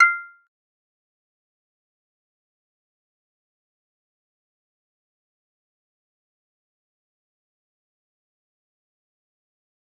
G_Kalimba-F7-mf.wav